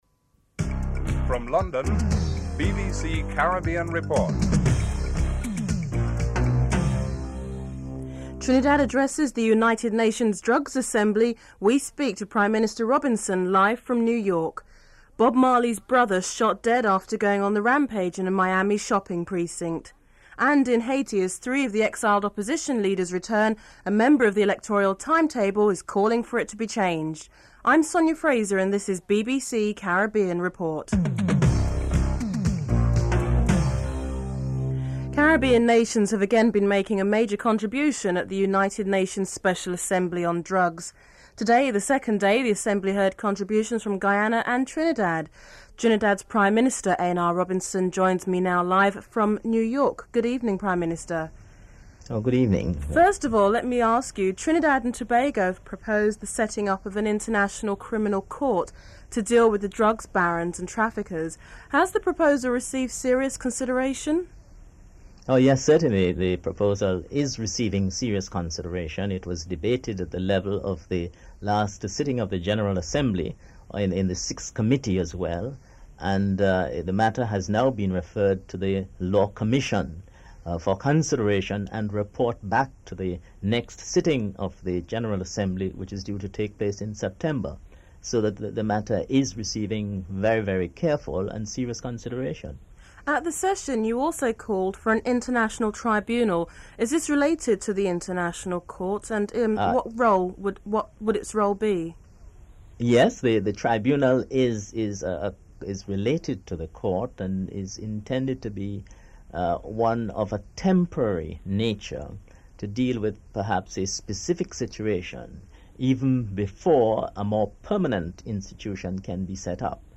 Includes musical interlude at the beginning of the report.
Interview with A.N.R. Robinson, Prime Minister of Trinidad and Tobago (01:31-05:25)